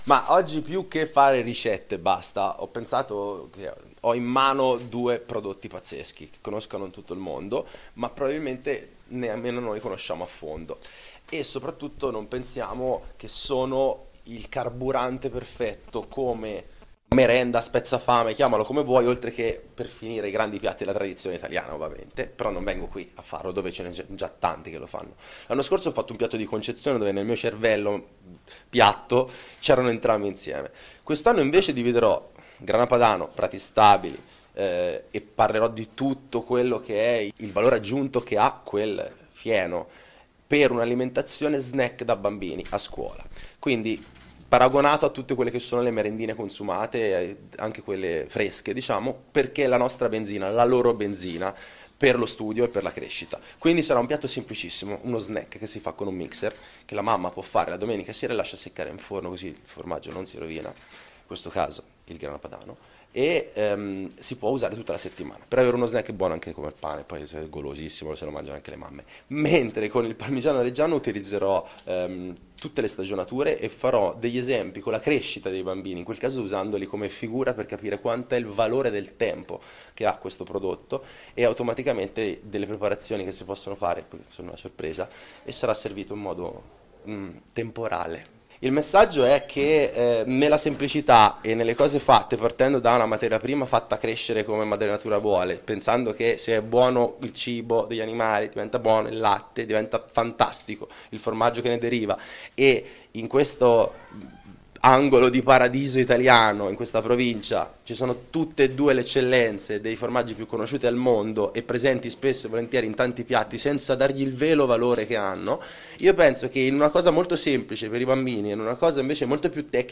I piatti rispecchiano quelli che sono i valori dello chef, ovvero semplicità e qualità delle materie prime, come da lui stesso confermato al nostro microfono: